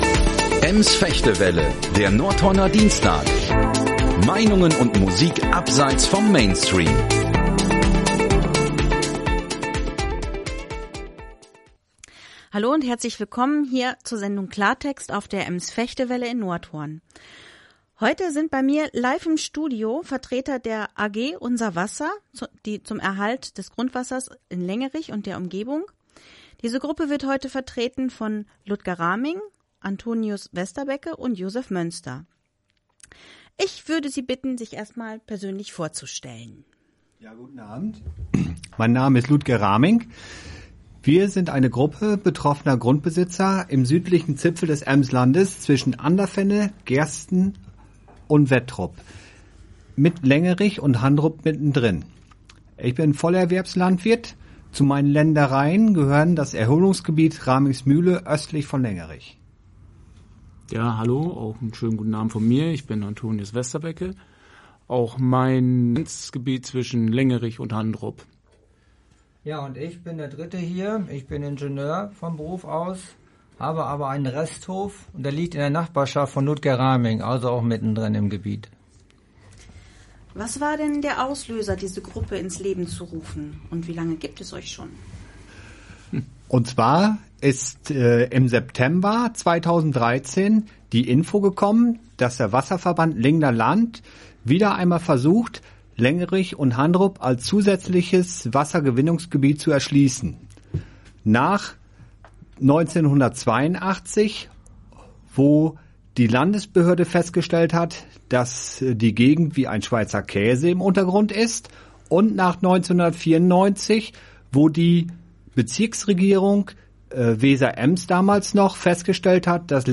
Am 18. Dezember 2018 war es soweit: Wir waren zu Gast im Studio des Bürgerradios Ems-Vechte-Welle – Kopfhörer auf, ‘Ruhe bitte’ und schon ging es los.
Das ganze Radiointerview können Sie hier nachhören.